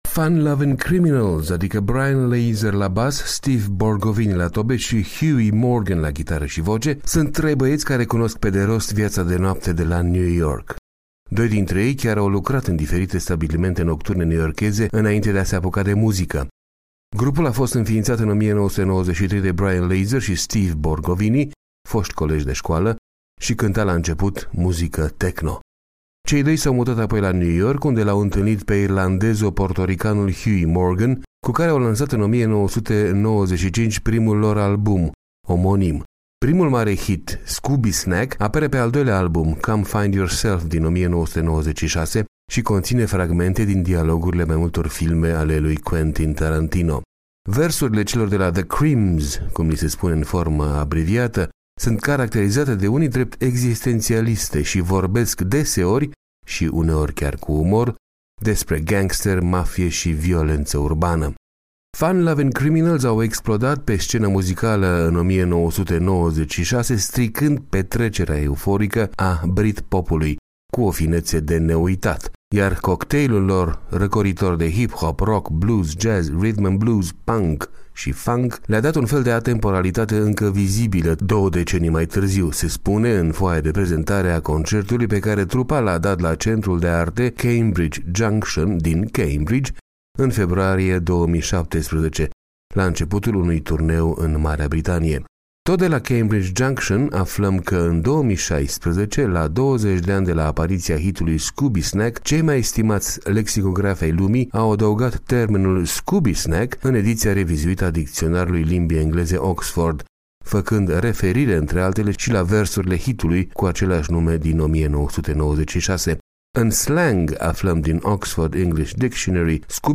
Fun Lovin’ Criminals, adică Brian Leiser la bas, Steve Borgovini la tobe, și Huey Morgan la ghitară și voce, sînt trei băieți care cunosc pe de rost viața de noapte de la New York.